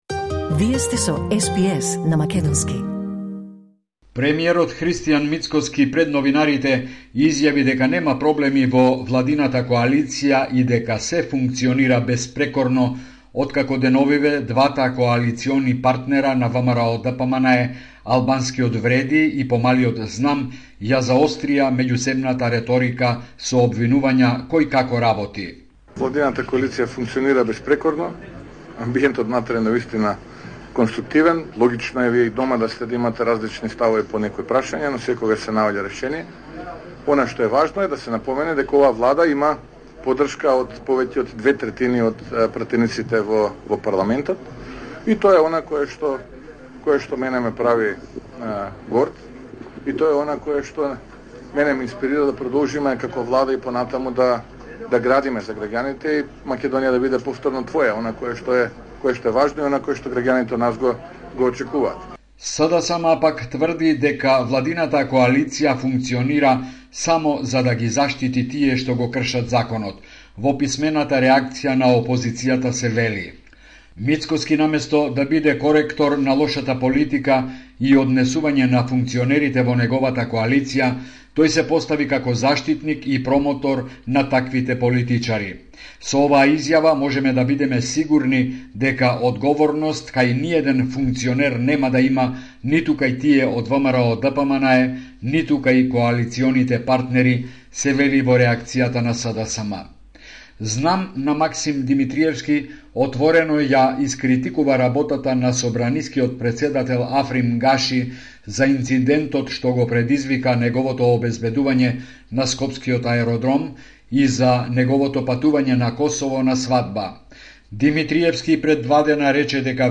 Homeland Report in Macedonian 23 August 2024